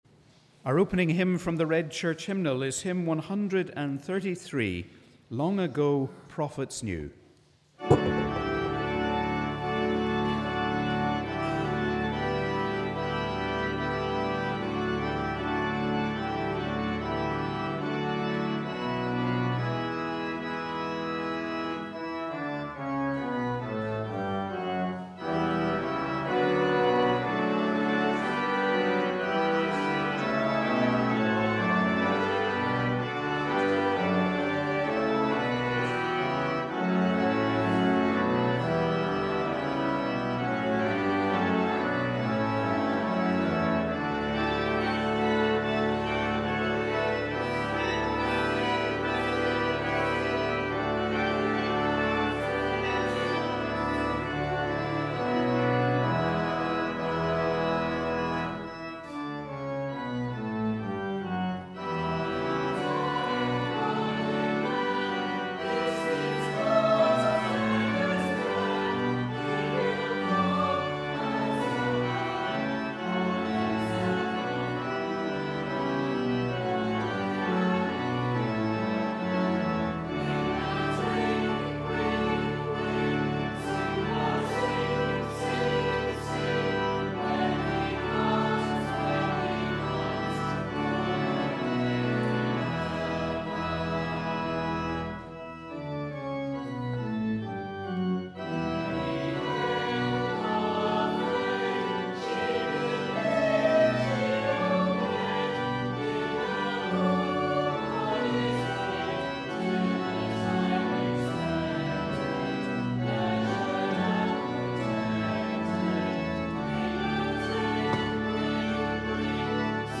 We warmly welcome you to our service of Morning Prayer for the fourth Sunday of Advent.